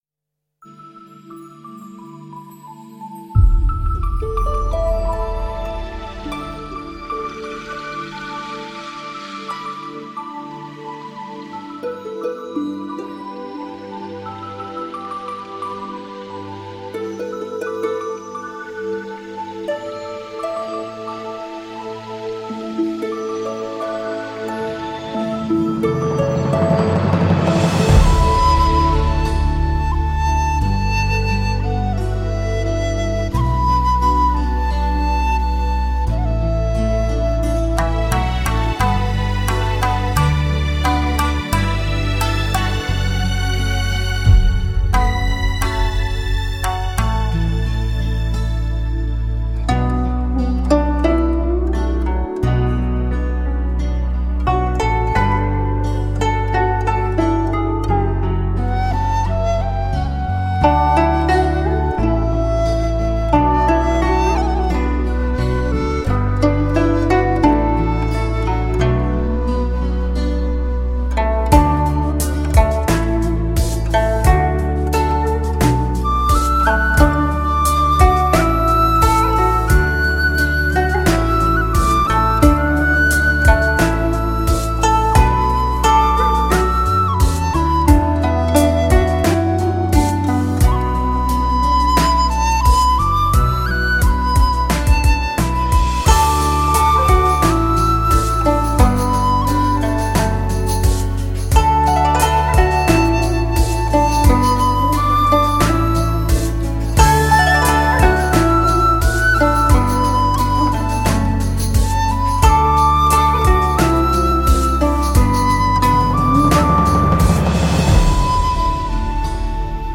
在“东风西渐”的潮流中，古筝以唯美、空灵、婉约的气质体现禅悦中的“空性、从容、淡定”；
笛子以自在、洒脱、灵动之美演绎登峰造极的复古情怀！